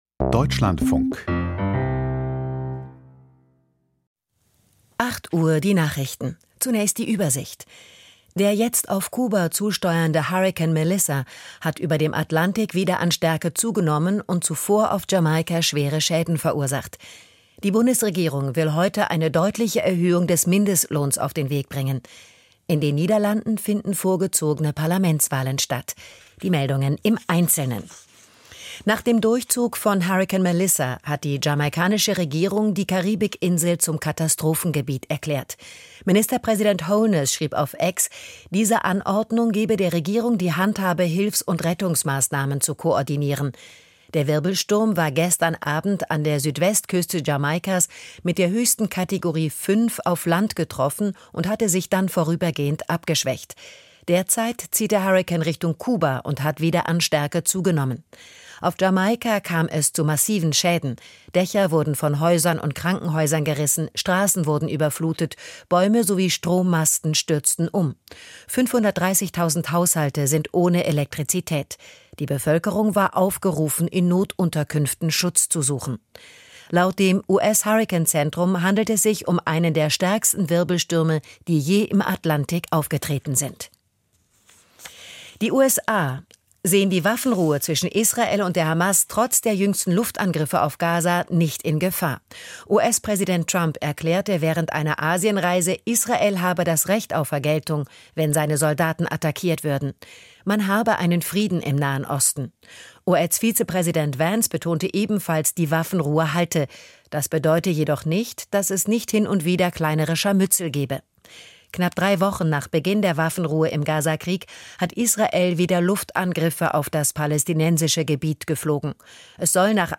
Die Nachrichten vom 29.10.2025, 08:00 Uhr
Die wichtigsten Nachrichten aus Deutschland und der Welt.